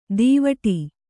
♪ dīvaṭi